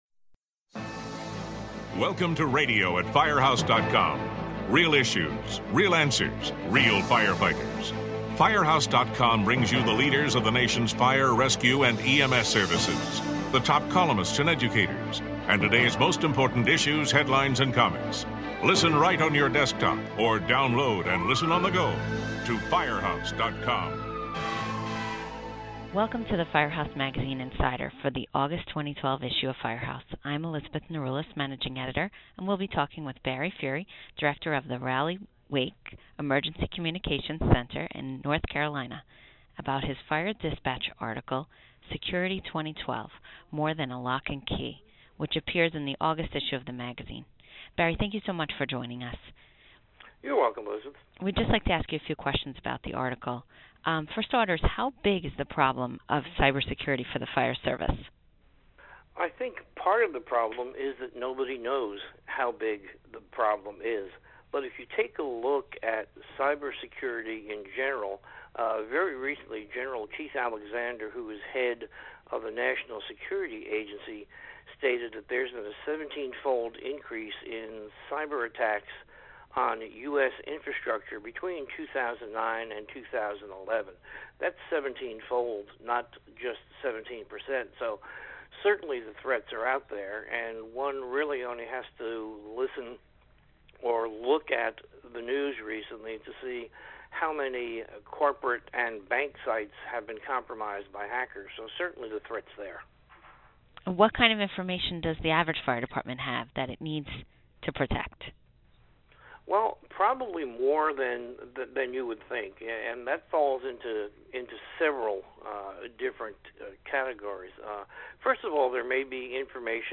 Listen as the two discuss modern security issues and what the biggest concerns are.